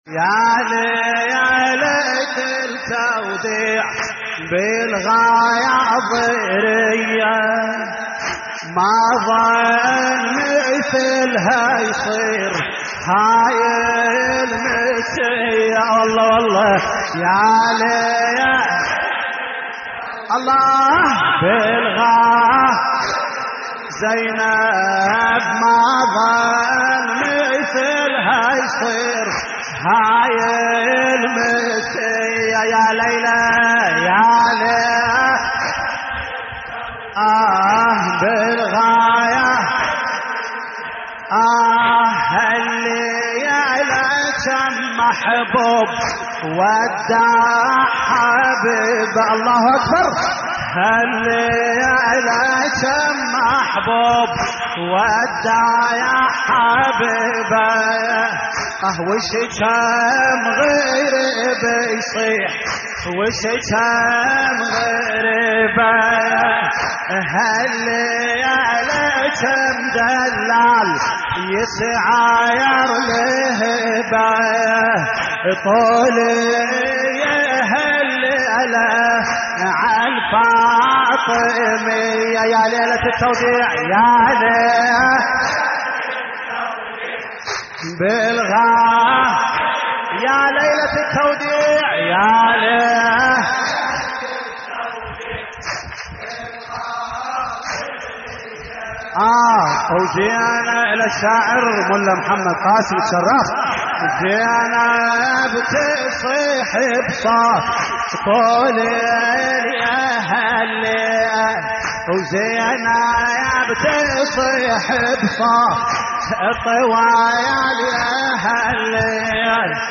تحميل : يا ليلة التوديع بالغاضرية ما ظن مثلها يصير هاي المسية / الرادود جليل الكربلائي / اللطميات الحسينية / موقع يا حسين